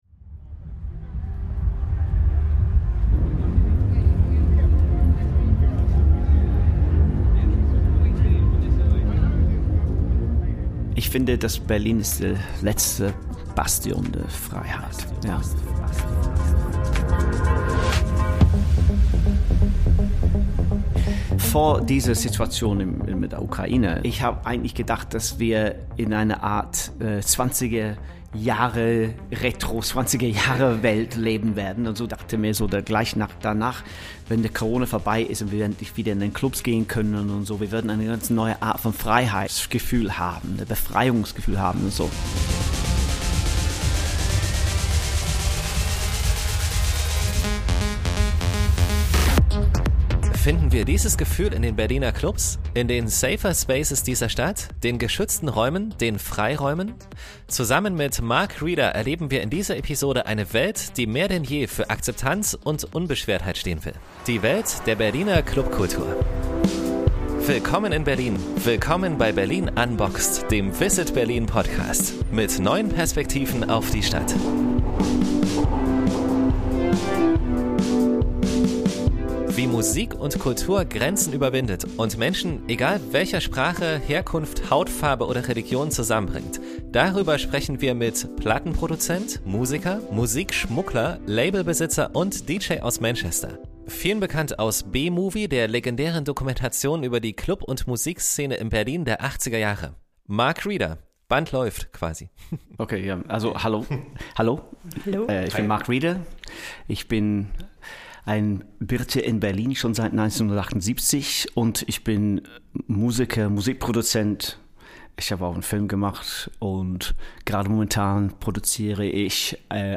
Beschreibung vor 3 Jahren Von der Musikszene und Clubkultur der 1980er Jahre in Berlin über Punk-Rock in der DDR, den es nur gab, weil er die bespielten Musik-Kassetten über die Grenze geschmuggelt hat, bis hin zu aktuellen Produktionen des britischen Musikers und Produzenten: In dieser Folge ist Mark Reeder bei uns zu Gast, ein Wahlberliner, der die Musikszene der Hauptstadt geprägt hat wie kein anderer. Wir sprechen mit ihm über den legendären B-Movie, über Musik und Clubkultur, über Ost- und Westberlin, über die 80er Jahre und heute – und natürlich darum, warum Berlins Musik-Szene wie keine andere für den Sound der Freiheit steht.